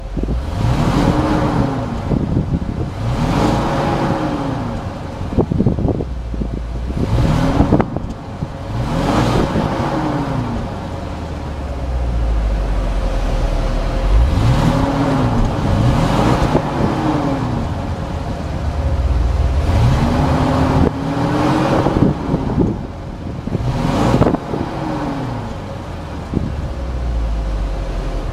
ENGINE SIZE 6.75 L V8